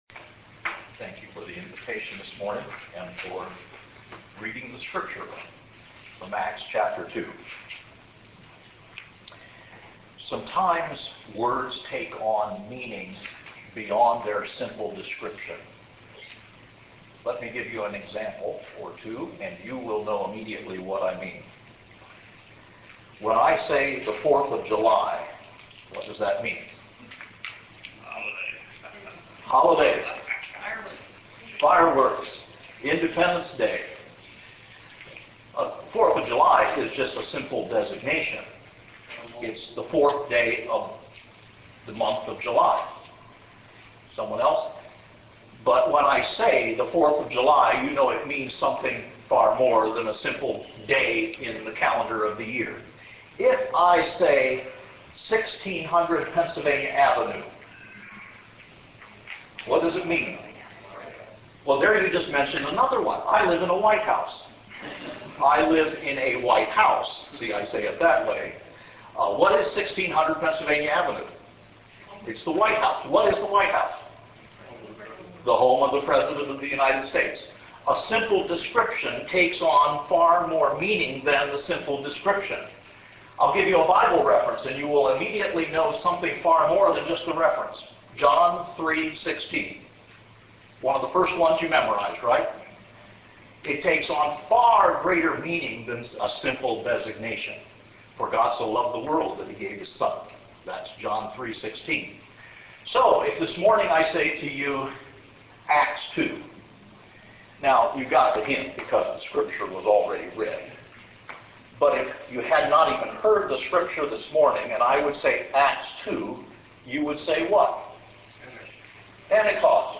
Selected Sermons